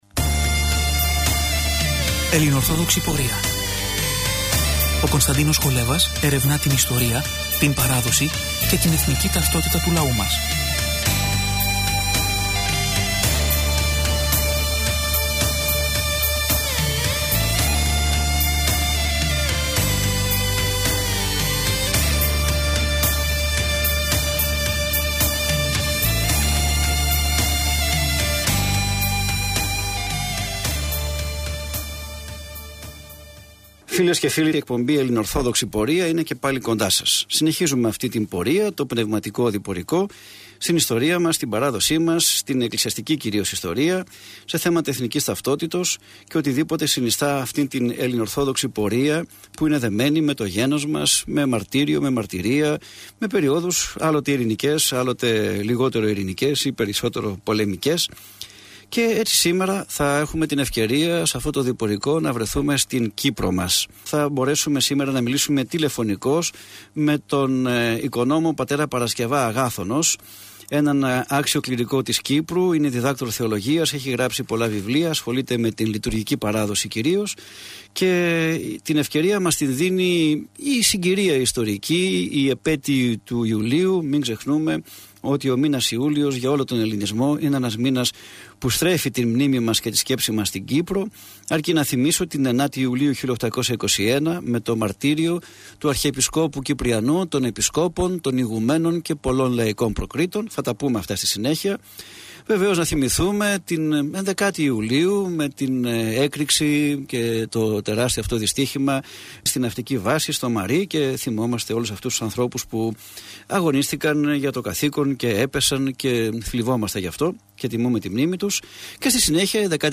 Παραθέτουμε στην συνέχεια, ηχογραφημένη εκπομπή του ραδιοσταθμού της Πειραϊκής Εκκλησίας